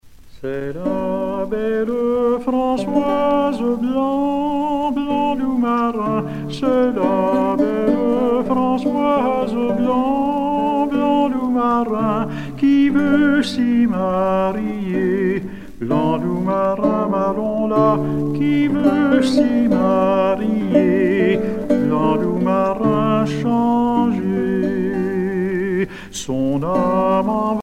Pièce musicale éditée